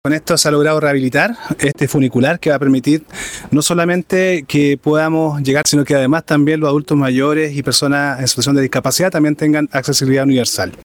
Angelo Montaño Espejo – Director SERVIU Coquimbo
Director-SERVIU-Funicular-.mp3